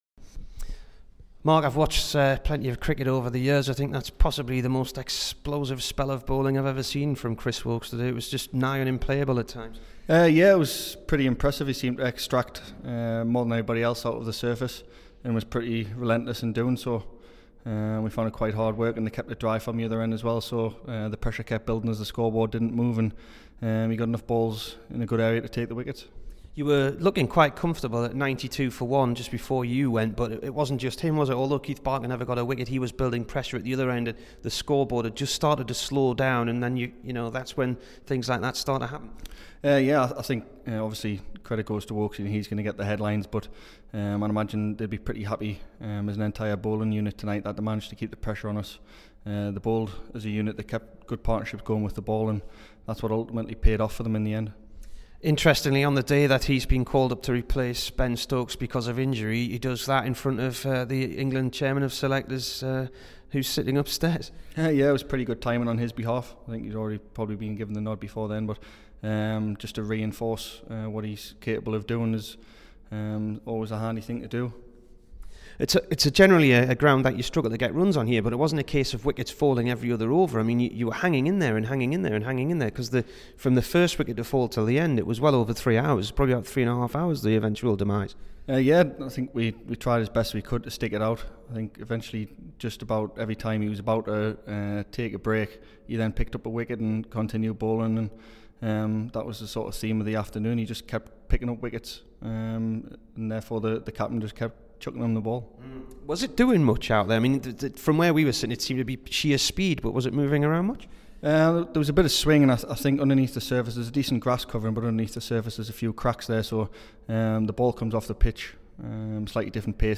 MARK STONEMAN INT
HERE IS THE DURHAM OPENER AND HIS THOUGHTS ON CHRIS WOAKES AND HIS 9-FOR TODAY FOR WARWICKSHIRE.